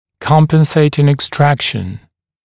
[‘kɔmpenseɪtɪŋ ɪk’strækʃn] [ek-][‘компэнсэйтин ик’стрэкшн] [эк-]компенсаторное удаление; удаление зуба-антагониста